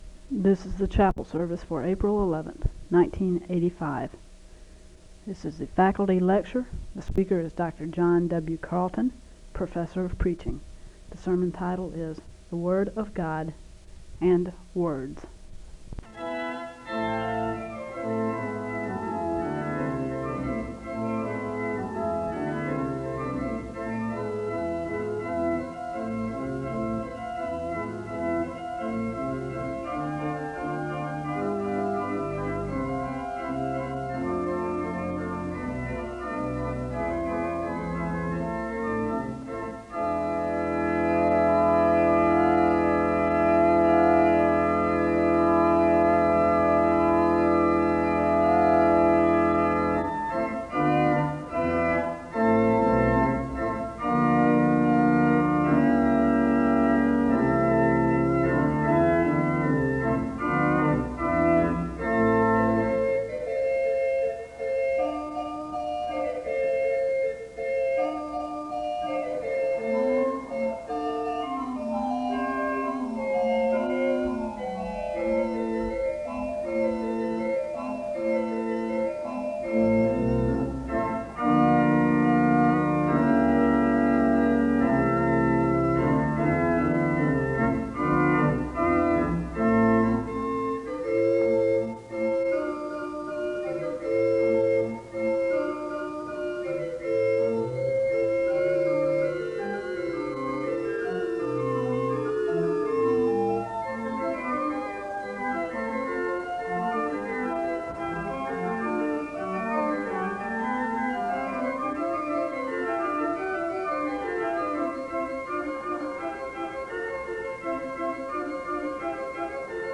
The service begins with organ music (0:00-4:57). There is a moment of prayer (4:58-6:25).
The service closes in a word of prayer (41:52-42:34).
In Collection: SEBTS Chapel and Special Event Recordings SEBTS Chapel and Special Event Recordings